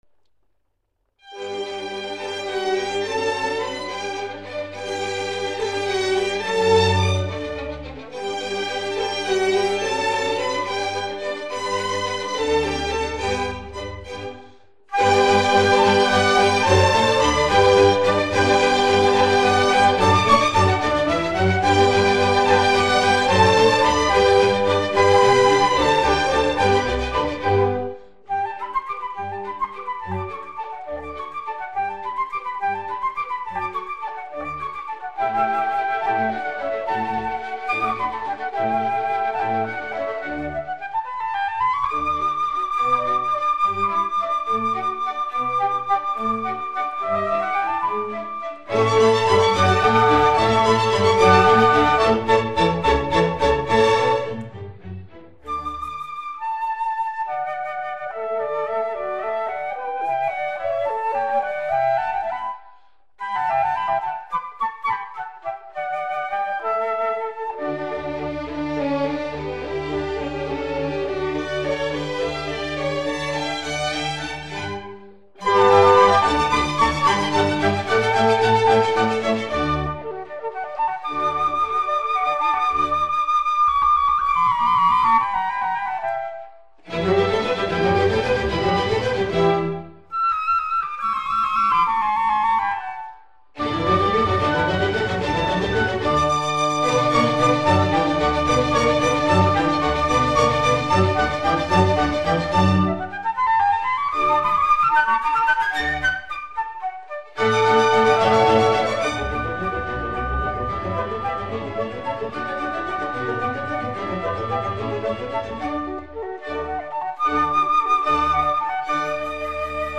Concerto pour deux flûtes - 3e mvt : Rondo-allegro